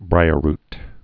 (brīər-rt, -rt)